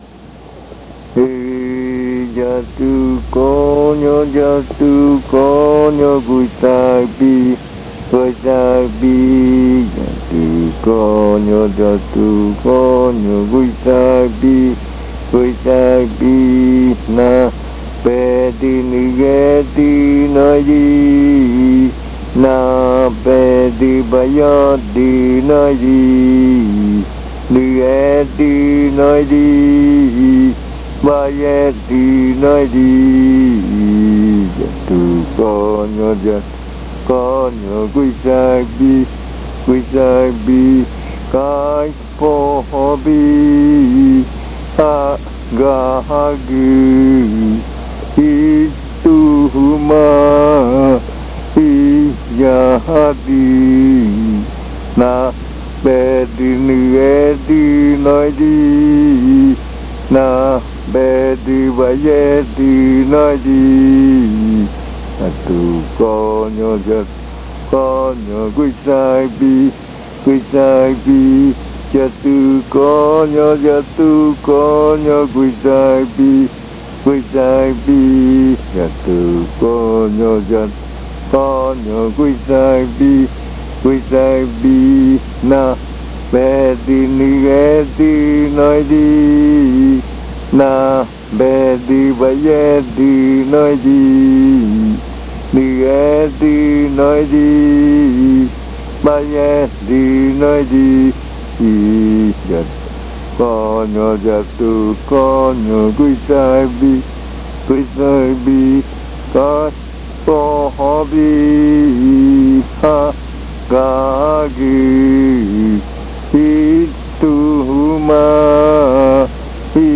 Leticia, Amazonas
Este canto hace parte de la colección de cantos del ritual Yuakɨ Murui-Muina
Cantos de yuakɨ